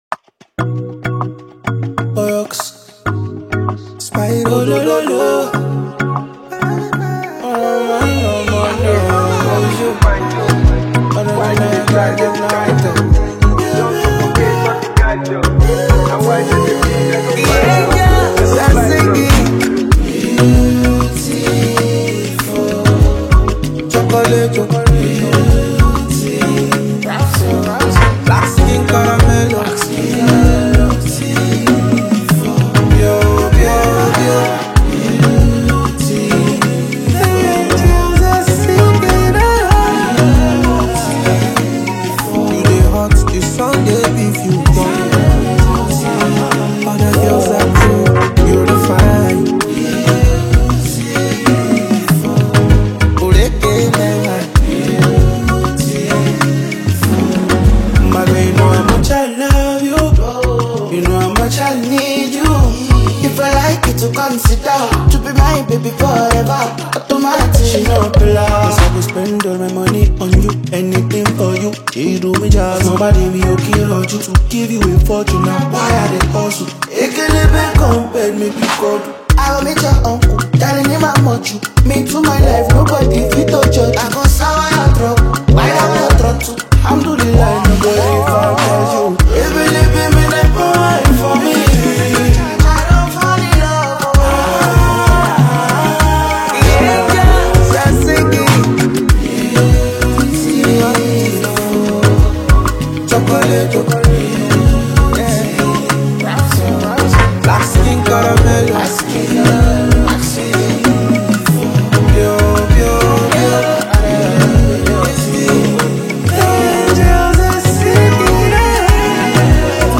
smooth and soulful vocals
retains the romantic essence of the original
signature vocal delivery and emotive expression.